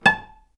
vc_pz-A5-ff.AIF